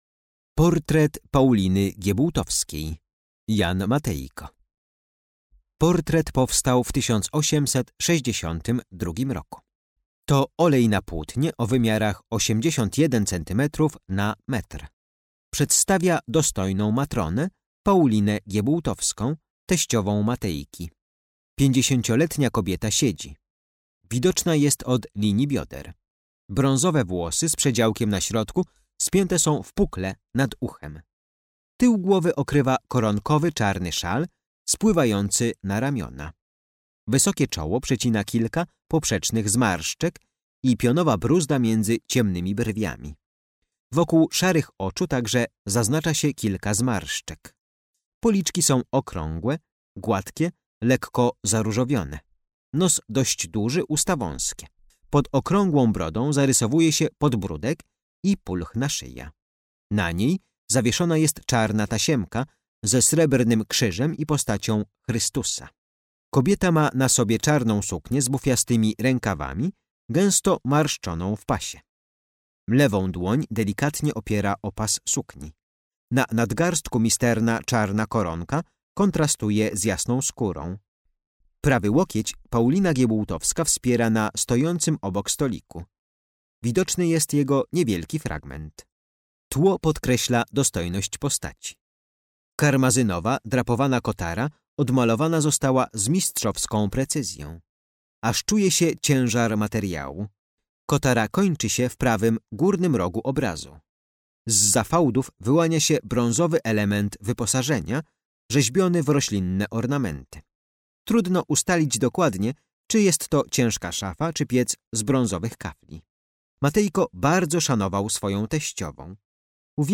Audiodeskrypcja dla wybranych eksponatów z kolekcji MNK znajdujących się w Domu Jana Matejki.